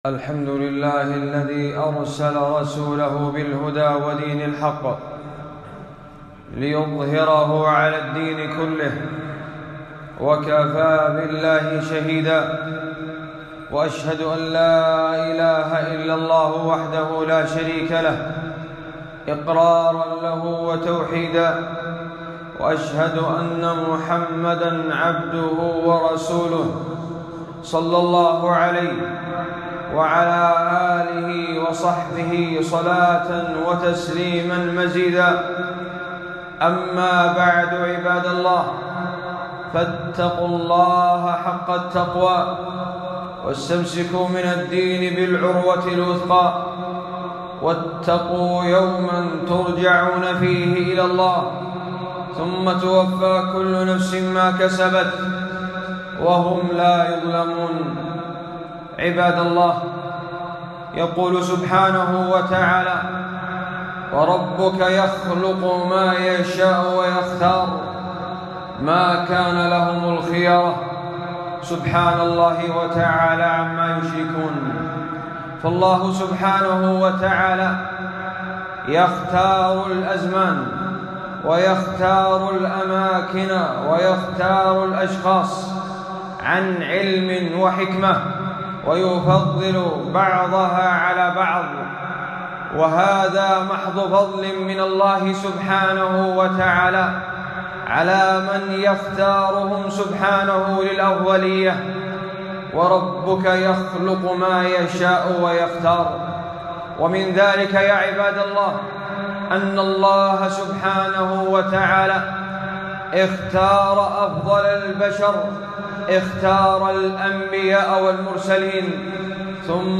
خطبة - فضل العلم والعلماء وعظيم فقدهم